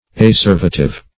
Acervative \A*cer"va*tive\, a.